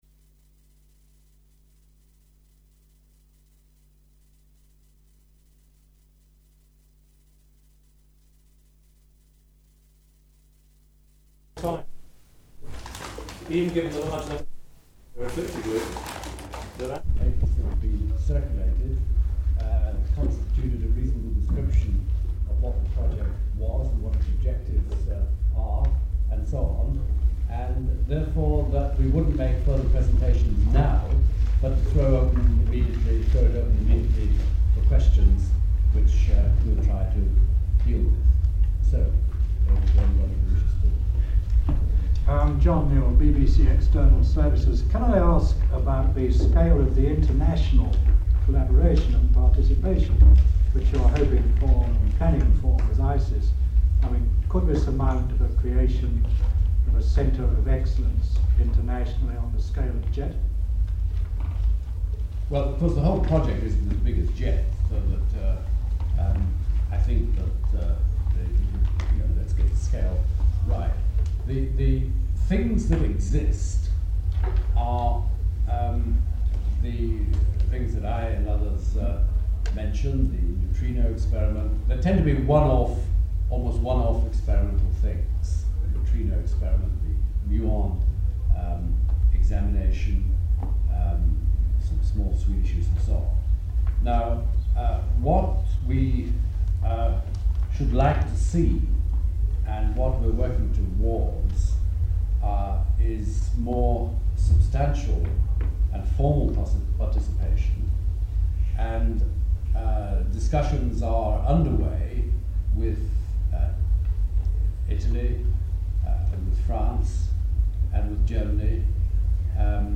isis-press-conference.mp3